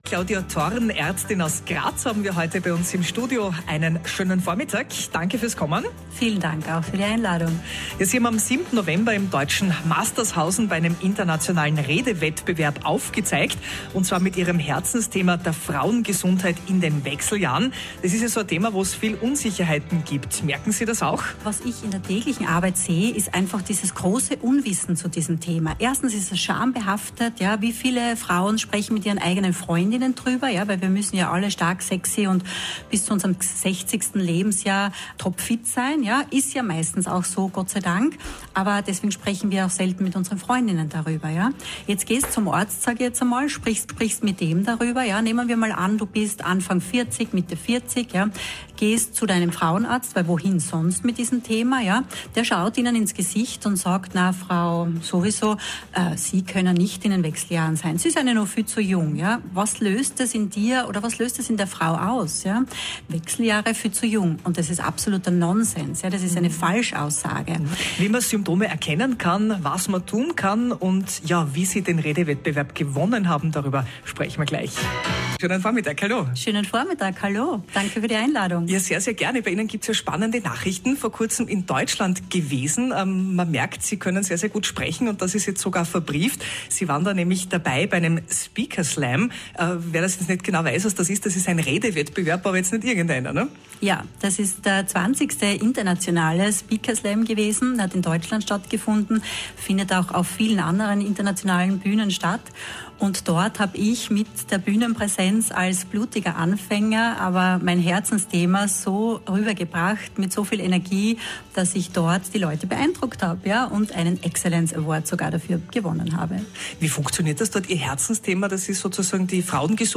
Heute nehme ich euch mit hinter die Kulissen eines großartigen Erlebnisses: Mein Interview bei Radio Steiermark!